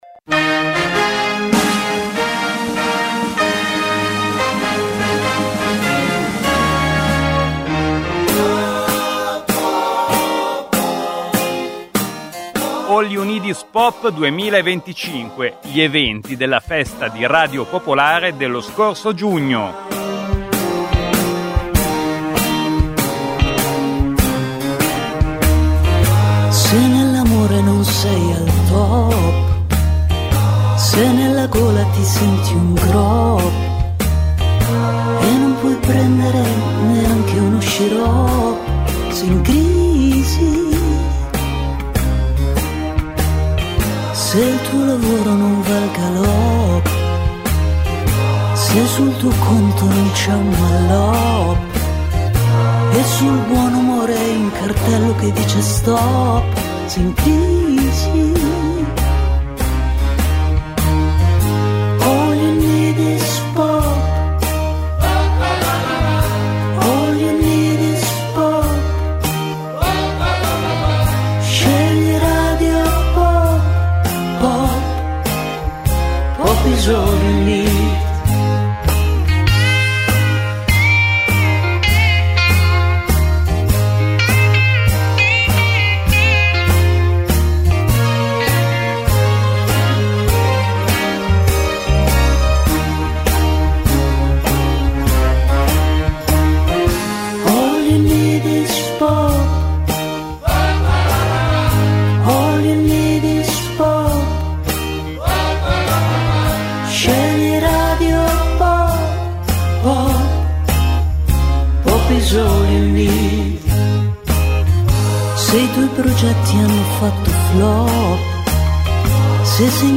Il meglio della festa di Radio Popolare, All You Need Is Pop del 6, 7 e 8 giugno 2025